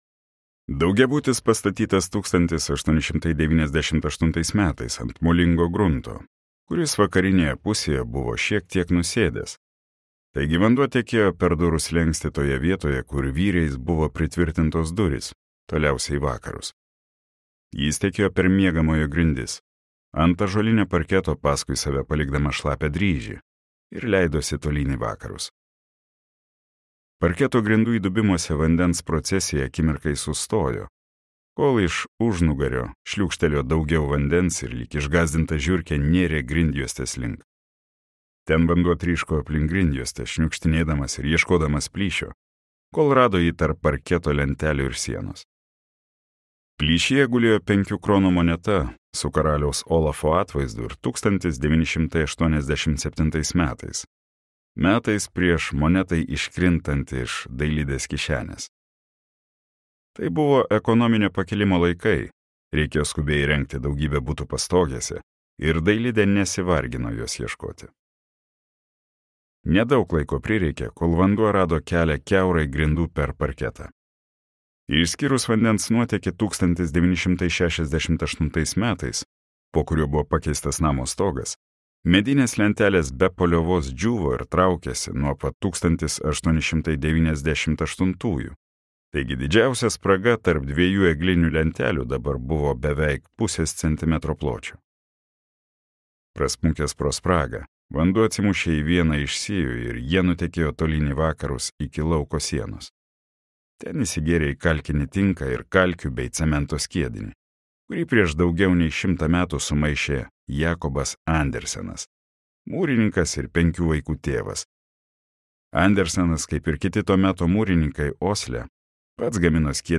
Pentagrama | Audioknygos | baltos lankos